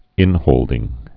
(ĭnhōldĭng)